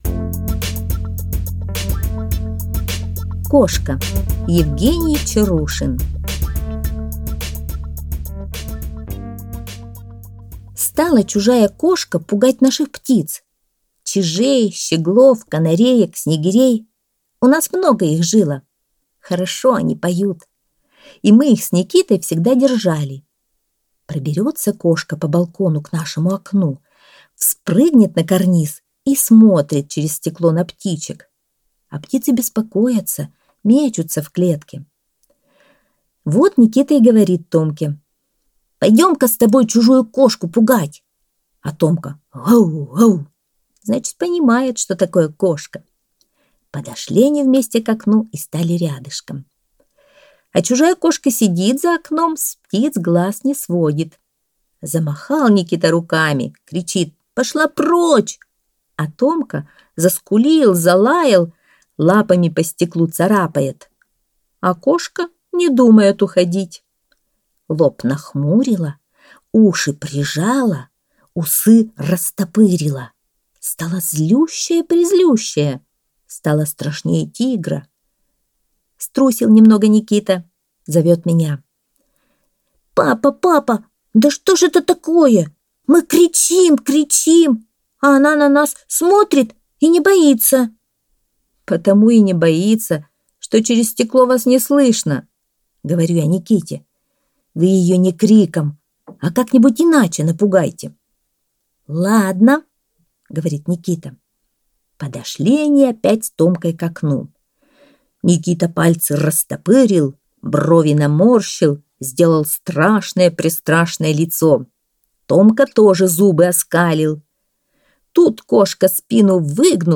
Аудиорассказ «Кошка»